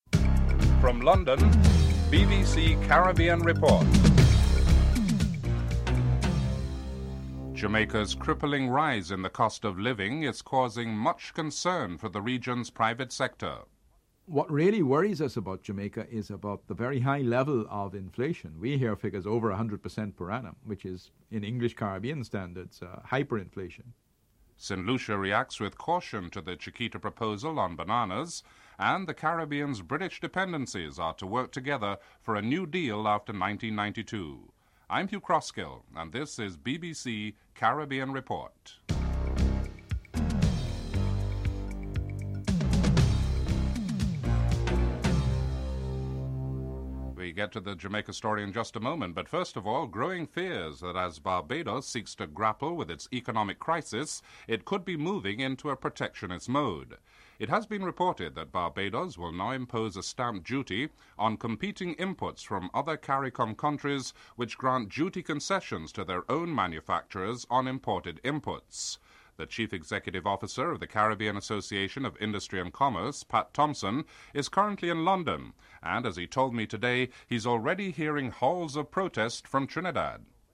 1. Headlines (00:00-00:45)